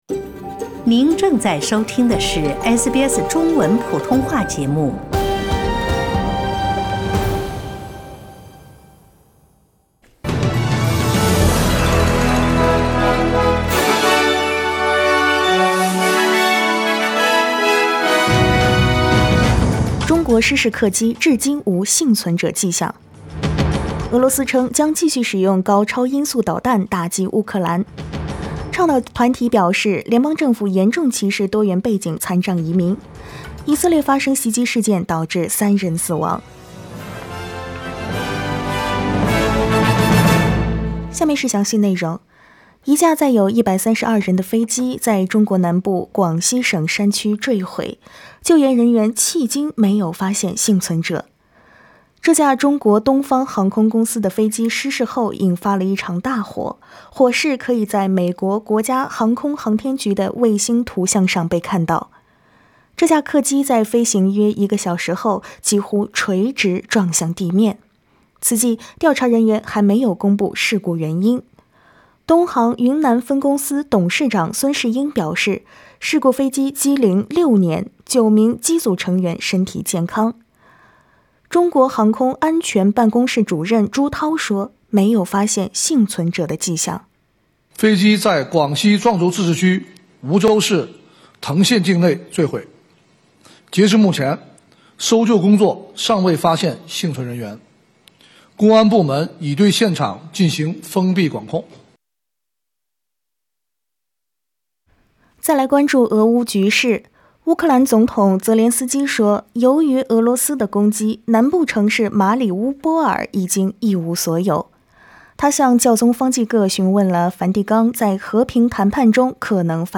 SBS早新闻（3月23日）
SBS Mandarin morning news Source: Getty Images